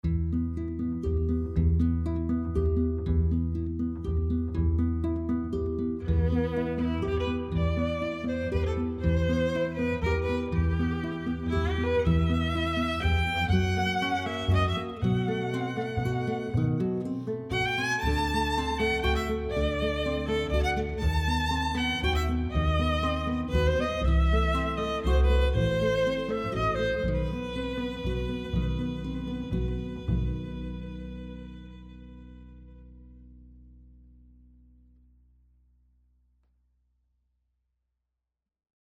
Piano
Violoncelle
Guitare
Violon
Contrebasse